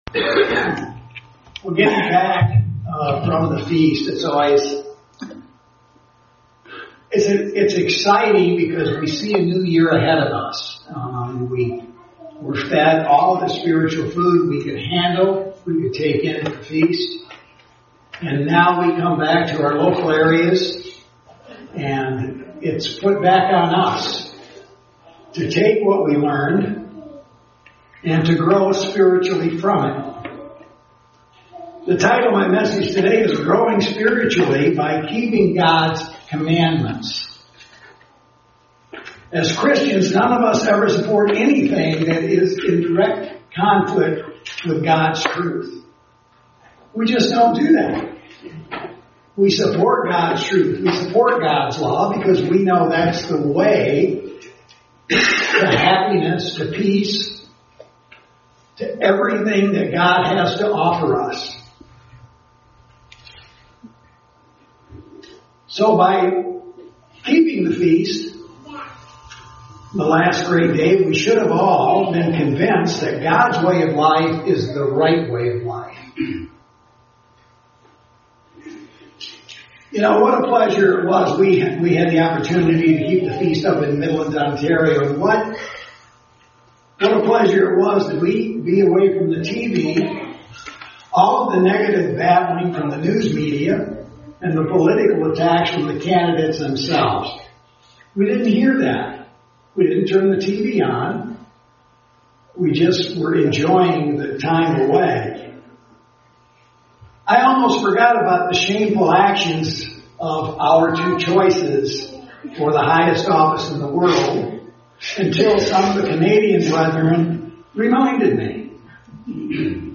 Given in Grand Rapids, MI